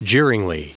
Prononciation du mot jeeringly en anglais (fichier audio)
Prononciation du mot : jeeringly